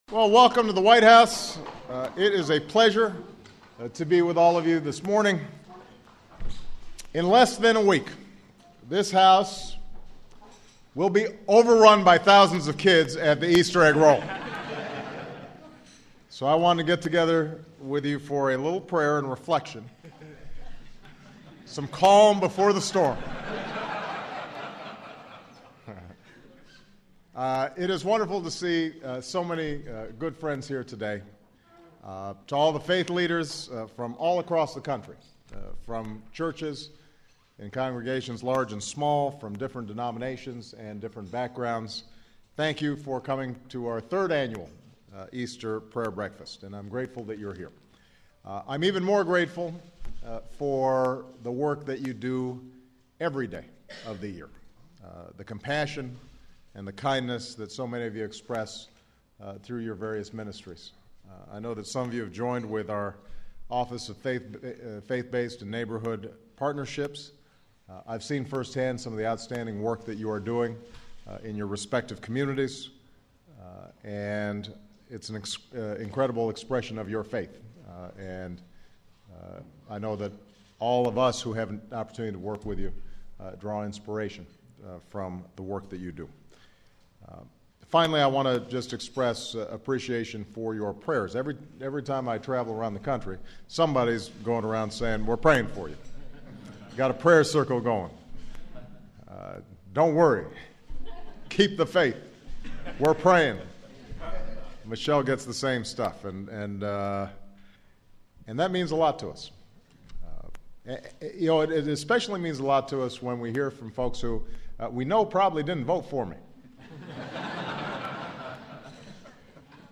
U.S. Presidents Barack Obama speaks at the third annual White House Easter Prayer Breakfast